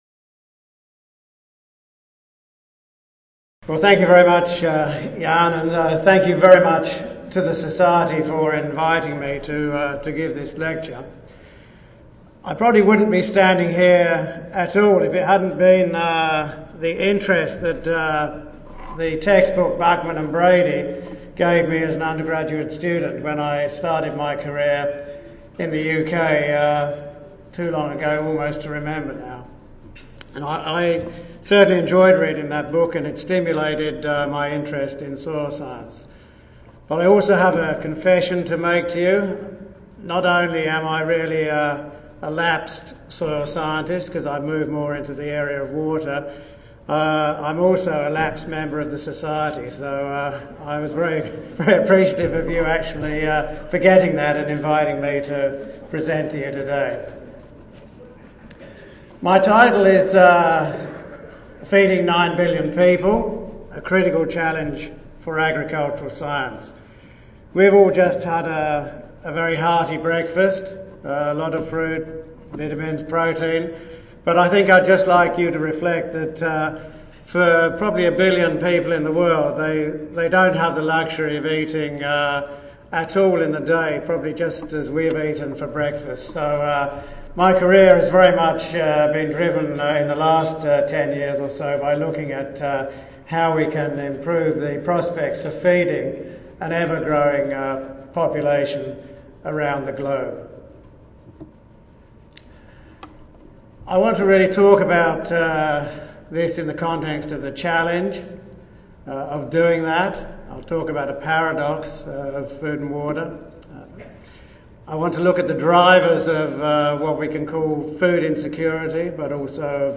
Oral Session
Marriott Tampa Waterside, Grand Ballroom E and F
Recorded Presentation